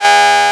ihob/Assets/Extensions/RetroGamesSoundFX/Alert/Alert18.wav
Alert18.wav